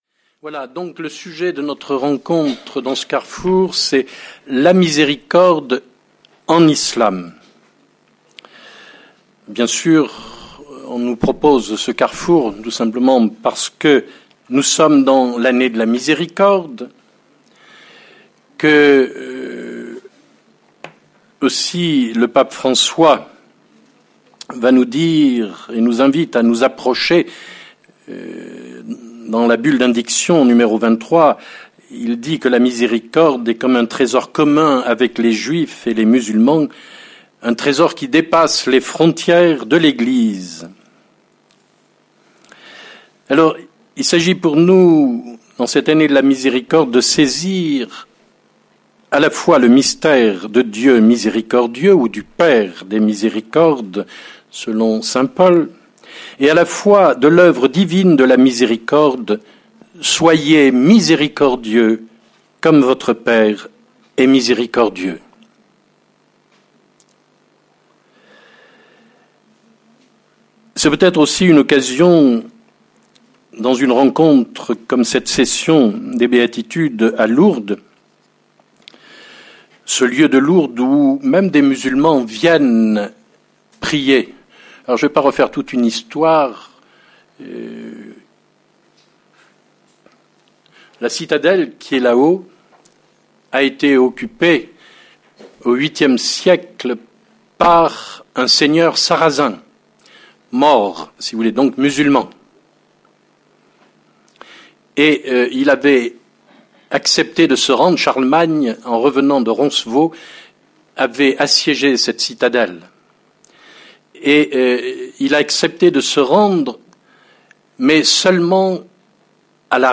Un enseignement passionnant et indispensable !